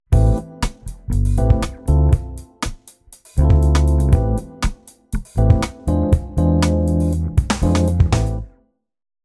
In these examples, our chord we are focusing on is used as the V7 going to the main key we started in indicated by the red arrows.